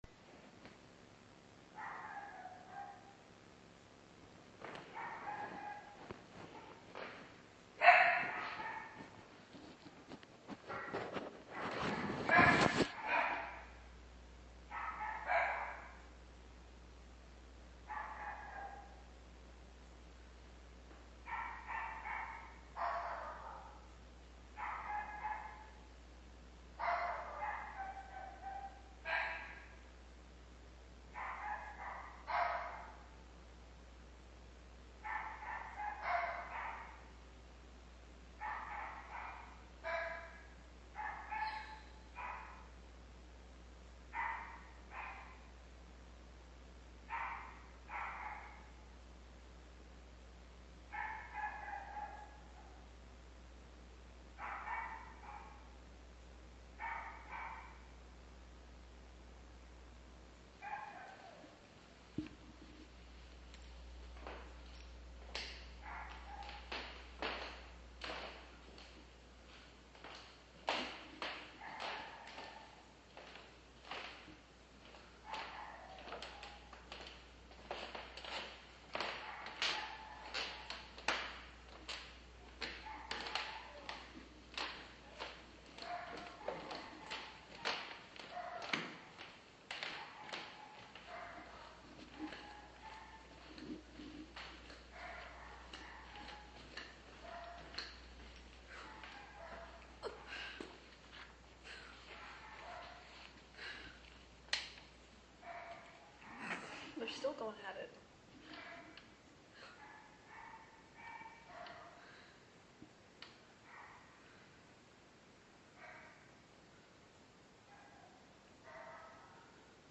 a Conversation Between Two Dogs
Here are two of my neighbours' dogs having a little conversation. They're right across the hall from each other. You can also hear the very creaky floors in my old building.